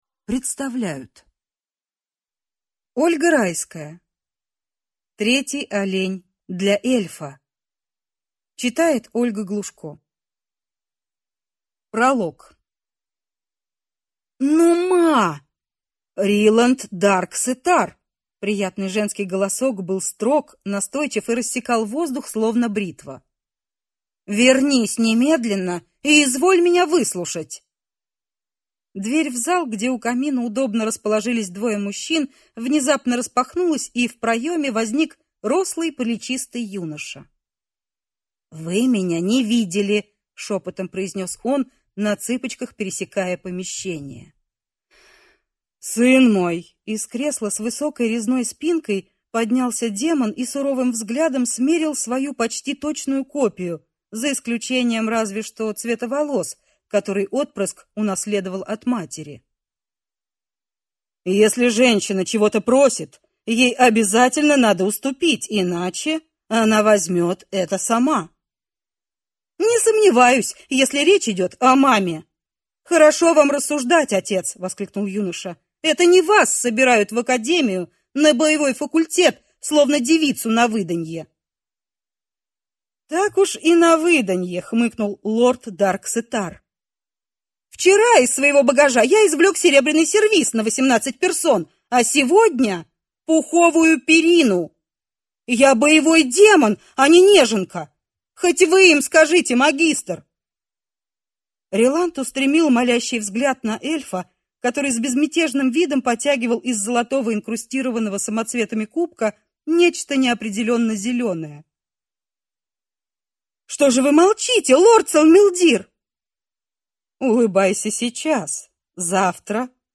Аудиокнига Третий олень для Эльфа | Библиотека аудиокниг